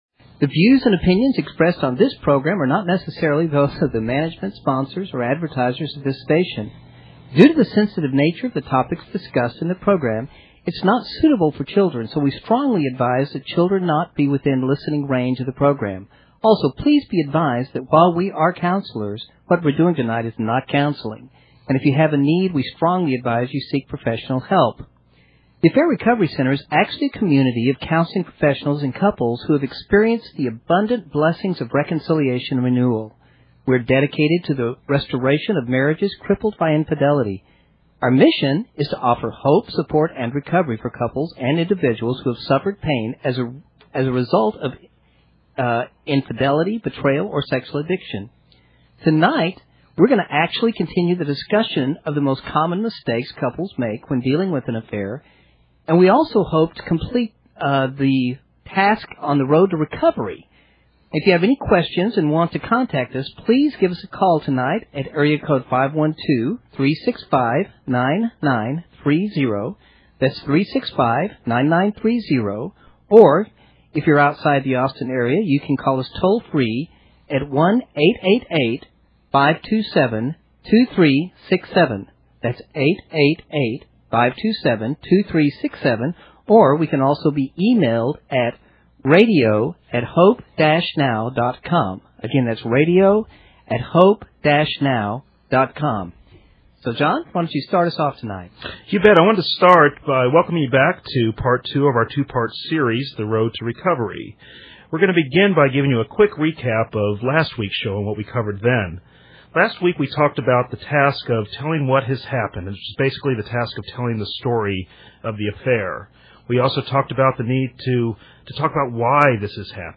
This week the ARC staff continues to review the road to recovery. They address the tasks Empathy Development, Forgiveness Commitment, Development of a Shared Marital History, Creation of a Marital Vision, Reclaiming Sexual Identity, and Recommitment. In addition, the staff comments on the questions of two callers who are experiencing problems in their recoveries.